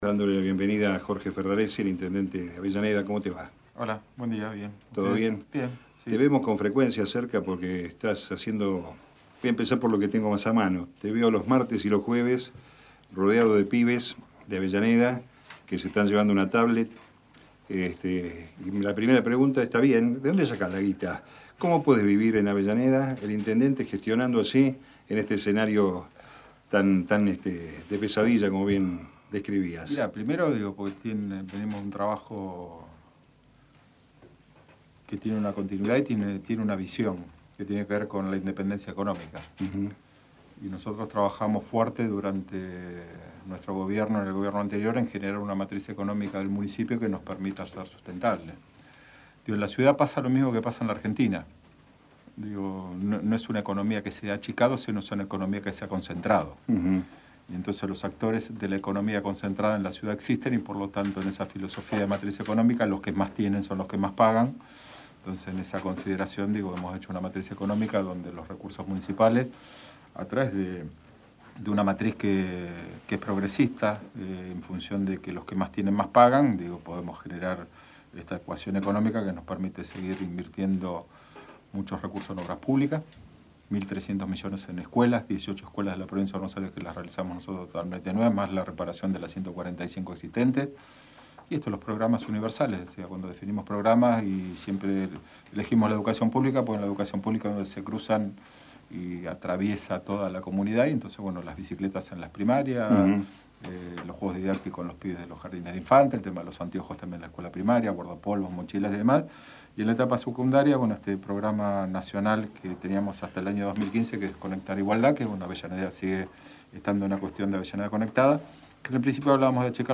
Meridiano Electoral 2019-06-25 Texto de la nota: Hoy en Meridiano Electoral nos visitó en el piso el intendente de Avellaneda Ing. Jorge Ferraresi Archivo de audio: 2019-06-25_MERIDIANO-ELECTORAL_JORGE-FERRARESI.mp3 Programa: Meridiano Nacional